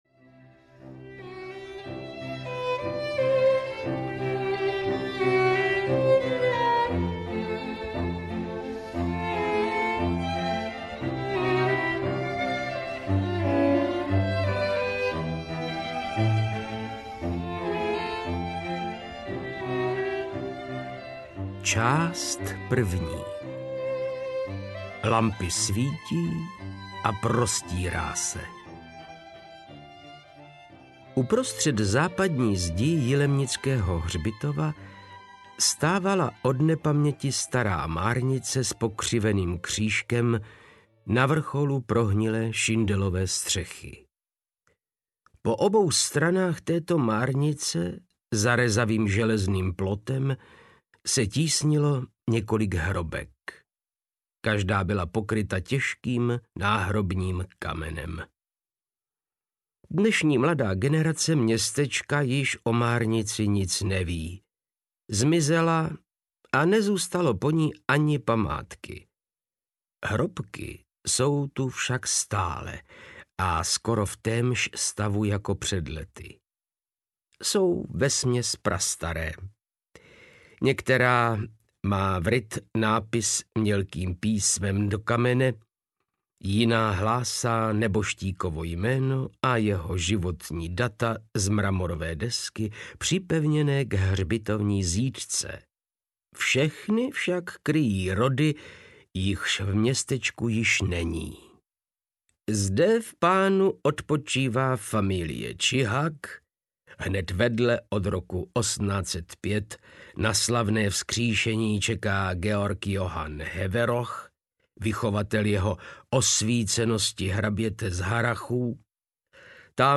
Petrolejové lampy audiokniha
Ukázka z knihy
• InterpretVáclav Knop